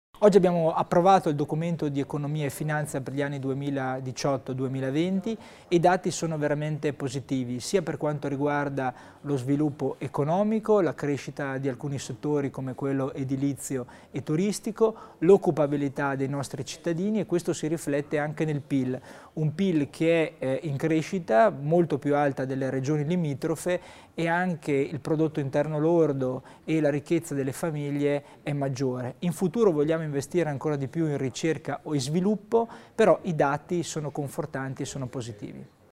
Il Vicepresidente Tommasini spiega le priorità del Documento di economia e finanza provinciale